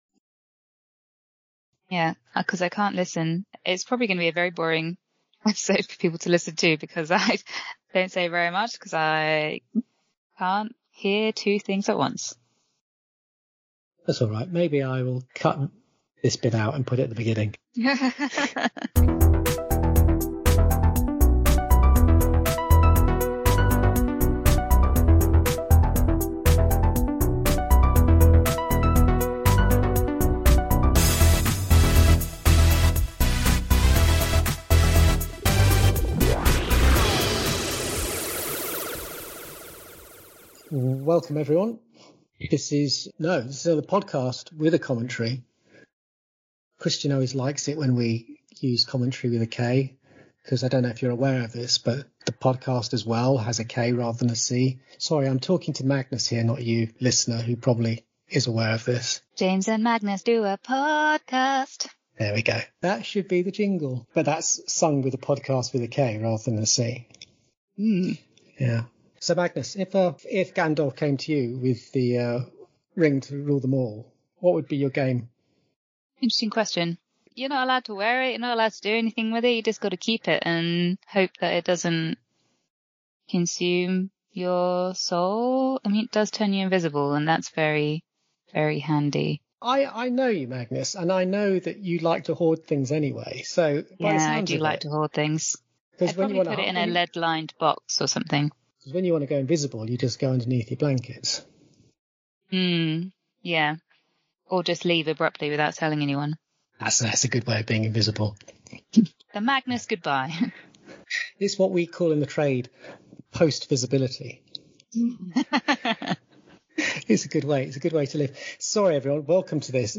An Unearthly Child Commentary